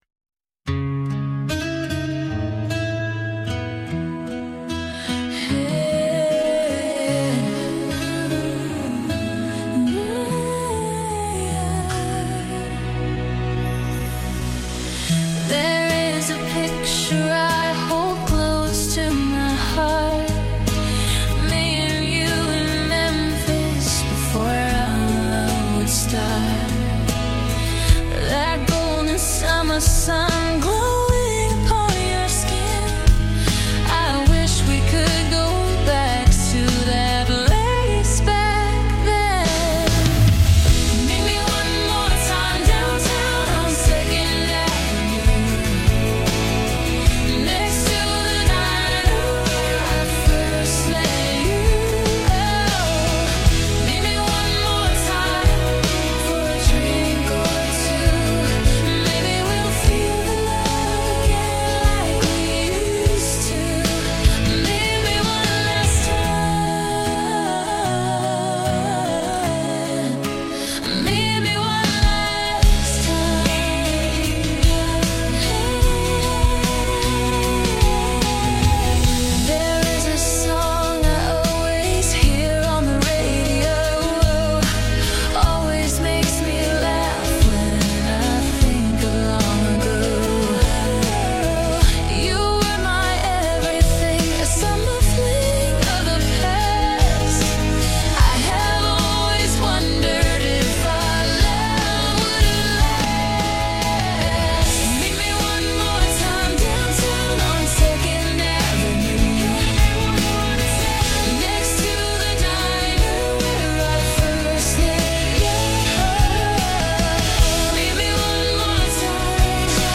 Adult Contemporary